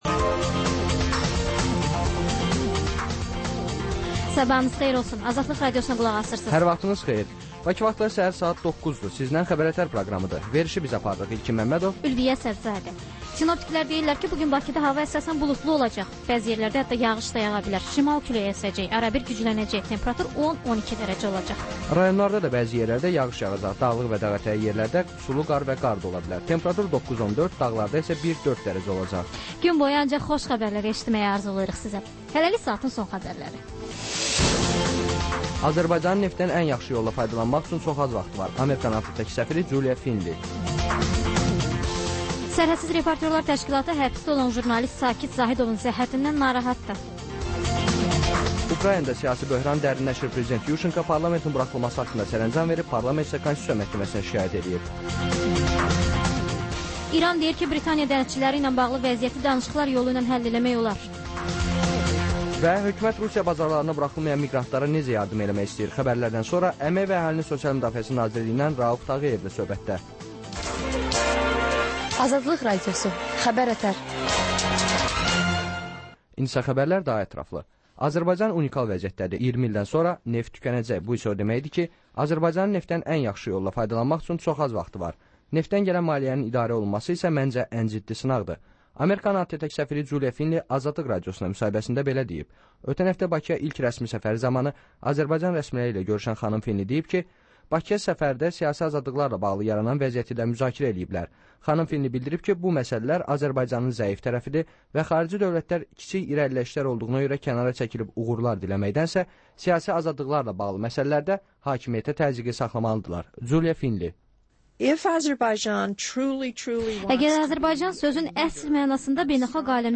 Xəbər-ətər: xəbərlər, müsahibələr, sonra TANINMIŞLAR verilişi: Ölkənin tanınmış simalarıyla söhbət